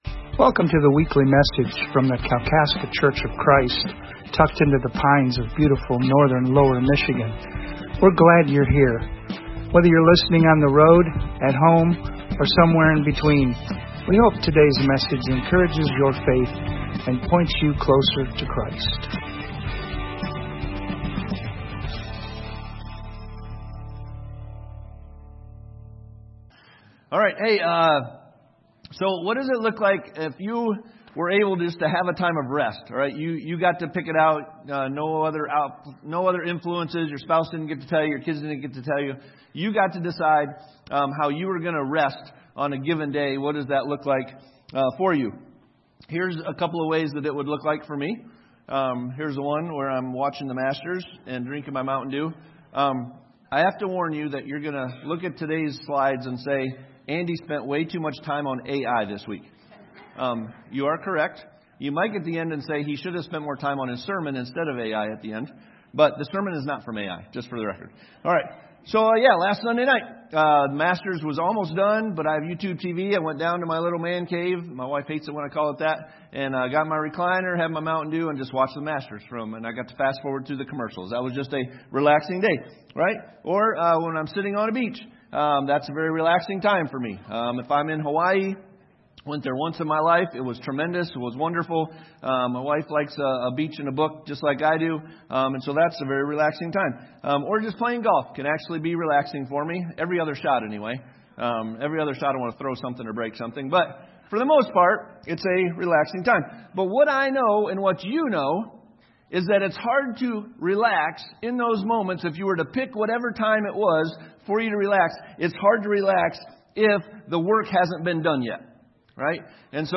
Sermon from April 19